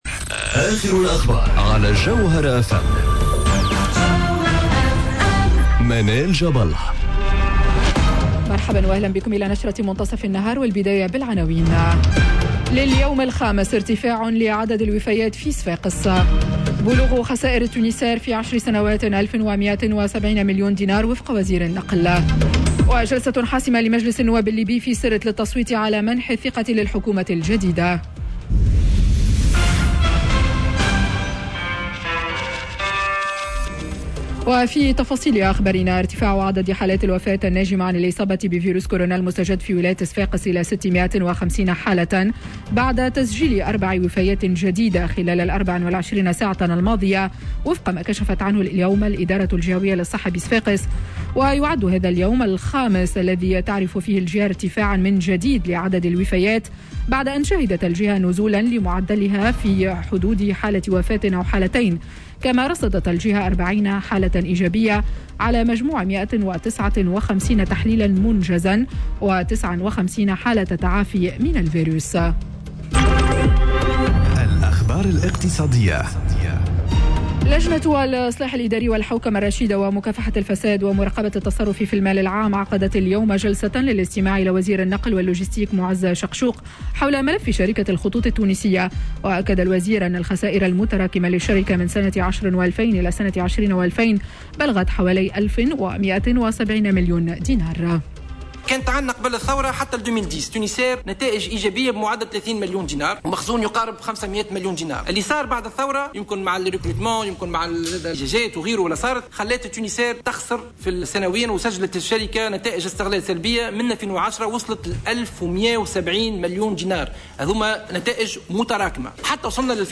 نشرة أخبار منتصف النهار ليوم الإثنين 08 مارس 2021